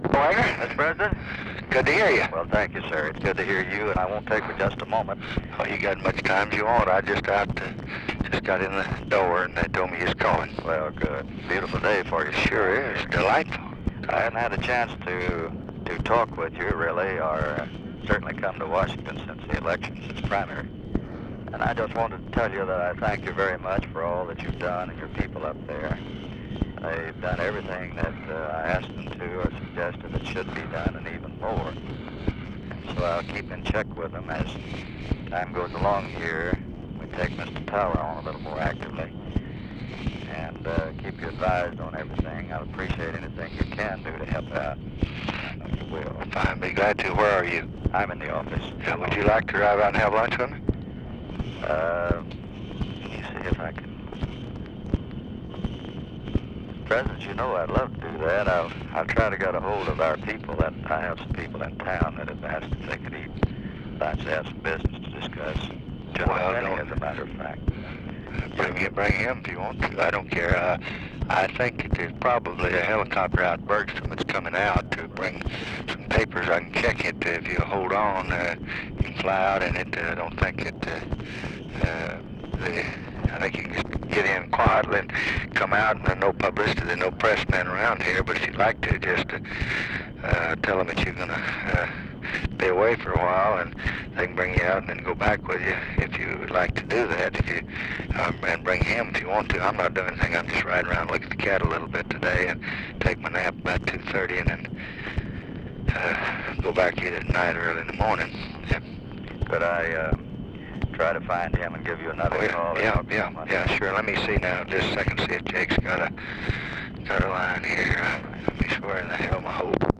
Conversation with WAGGONER CARR, June 6, 1966
Secret White House Tapes